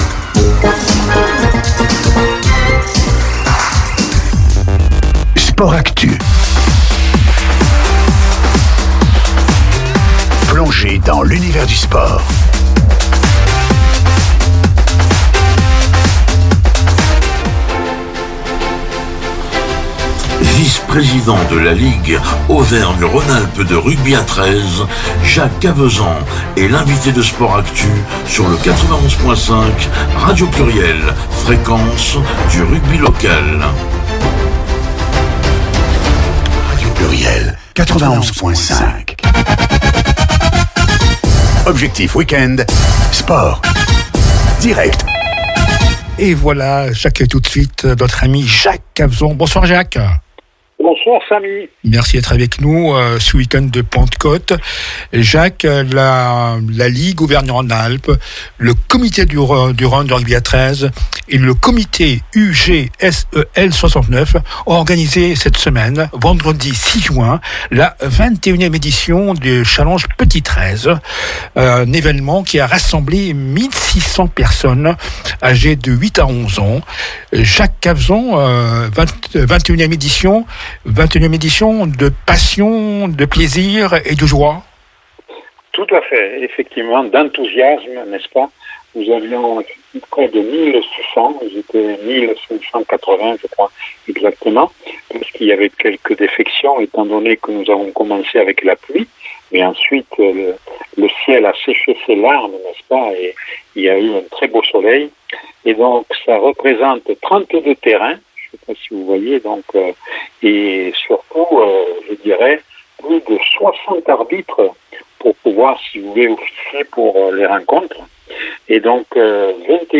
L’interview du président mois de JUILLET 2025